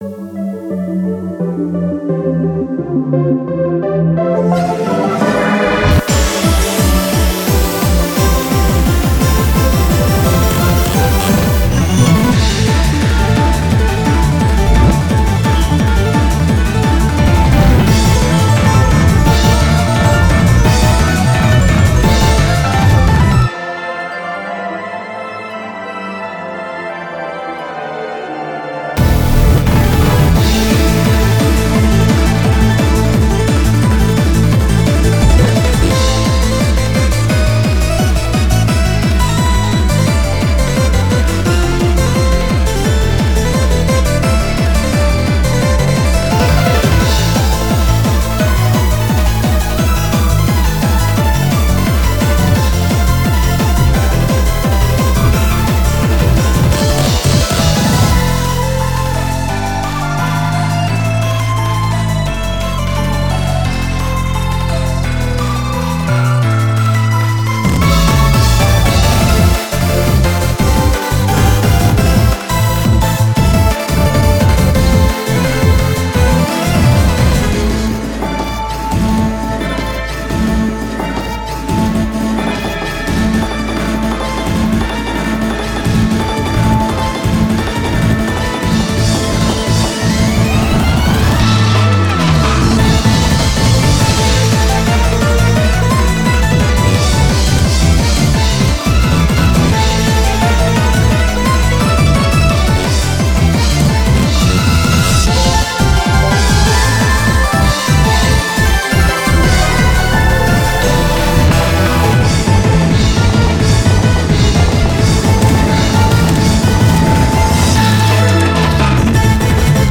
BPM87-346